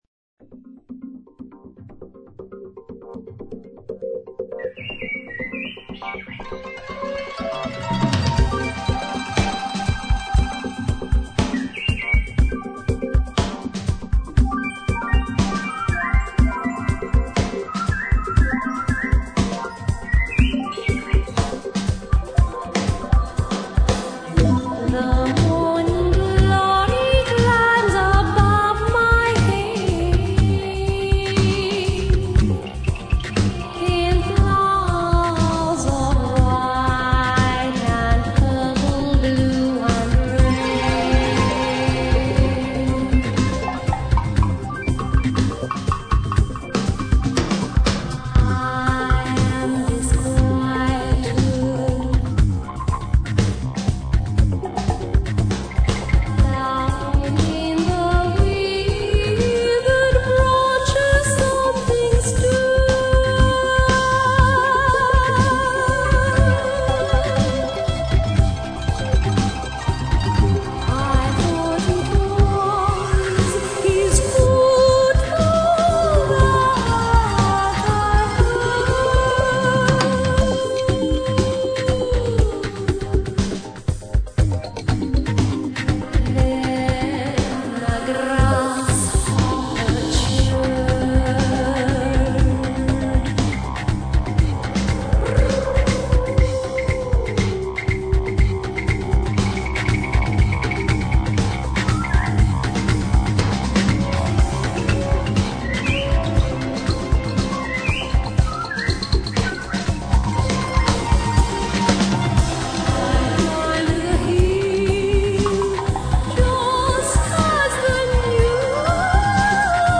Vocals
Drums
Saxophone
Violin, Viola
Congas
Panpipes [Zampoña]